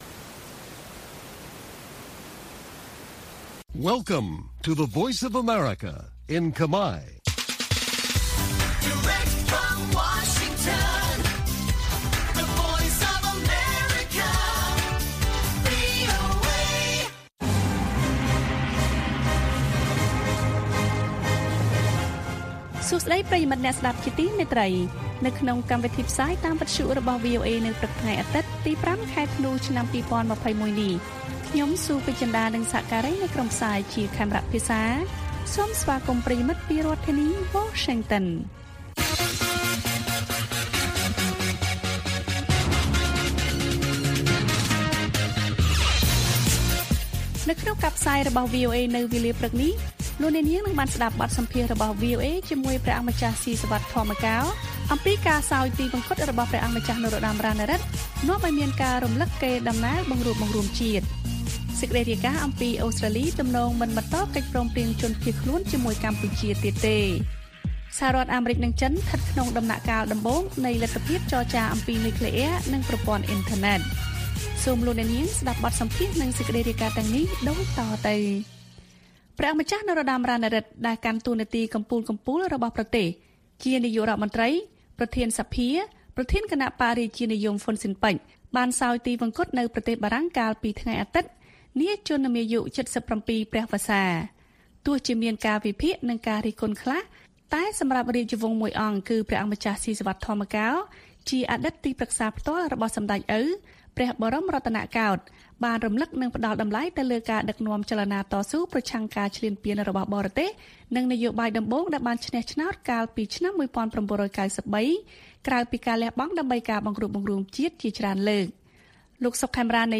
ព័ត៌មានពេលព្រឹក៖ ៥ ធ្នូ ២០២១